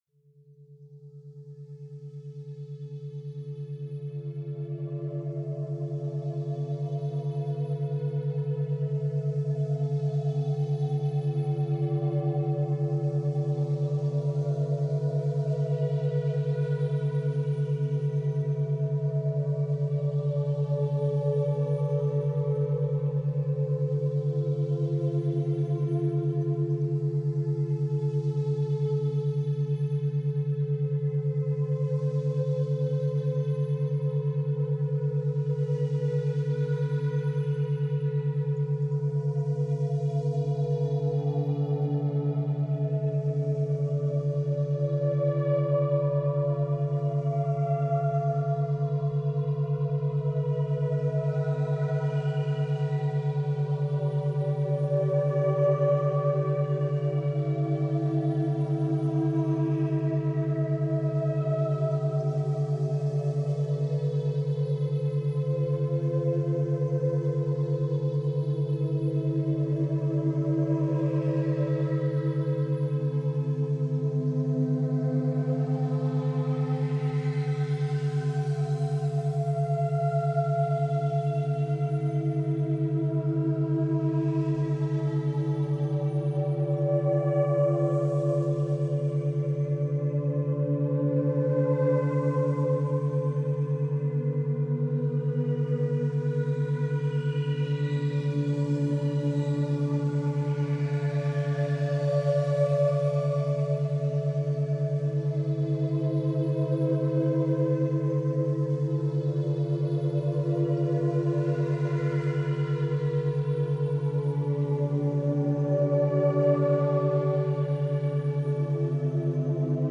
Es beginnt leise.